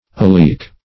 Oleic \O"le*ic\ ([=o]*l[=e]"[i^]k or [=o]*l[=a]"[i^]k or